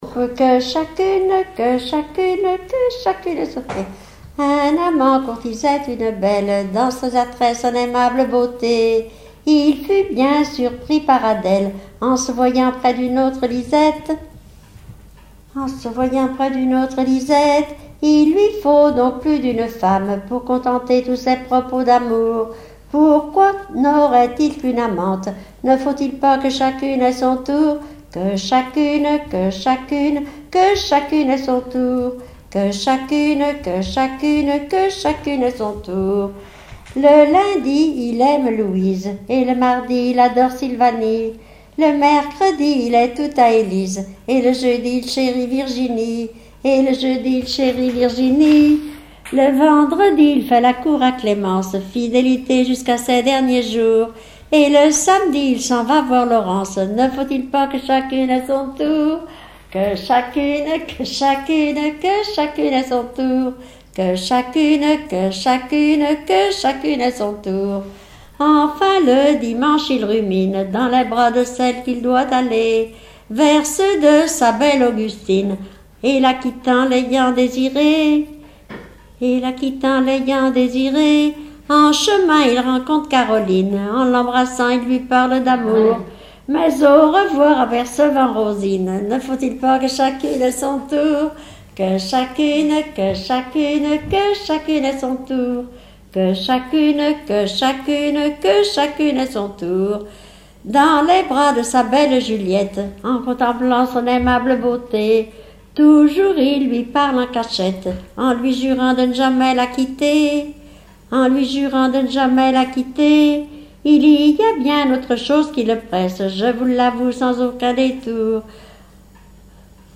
Genre strophique
Témoignages et chansons
Pièce musicale inédite